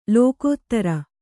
♪ lōkōttara